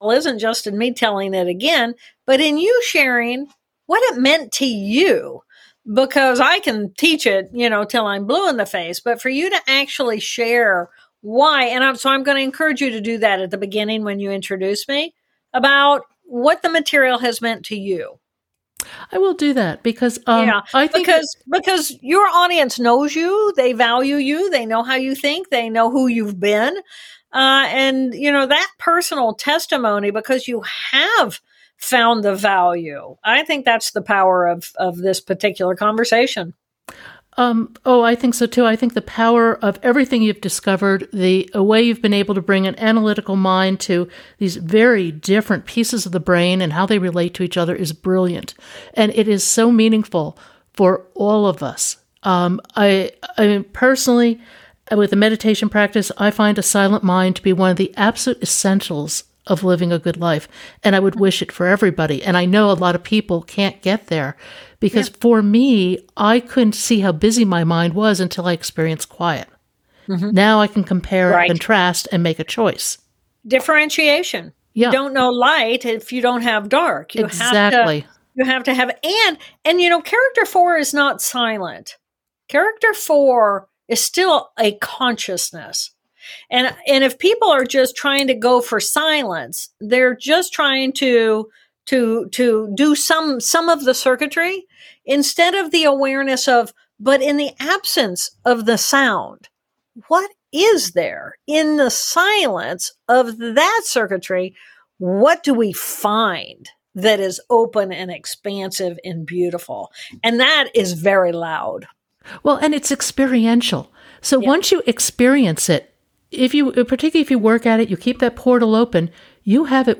In the second part of our conversation, we also explore the use of psychedelics for brain health; mental health in children; and more.